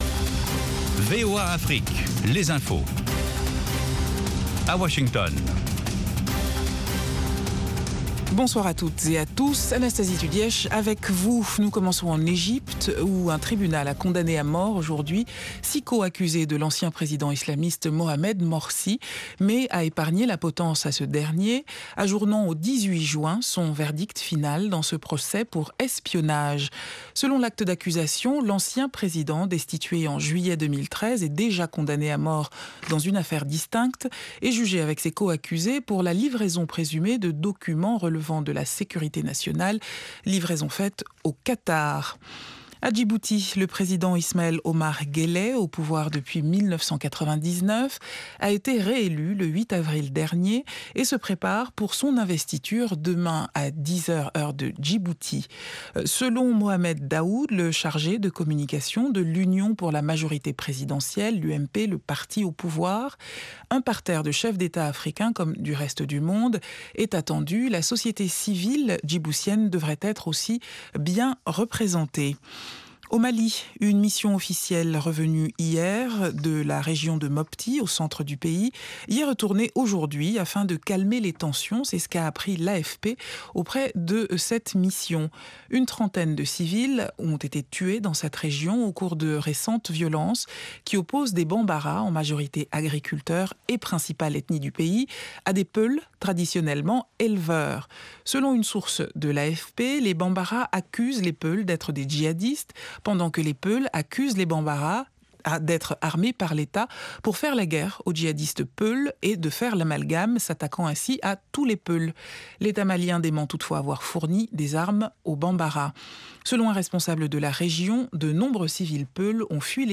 Bulletin
5min Newscast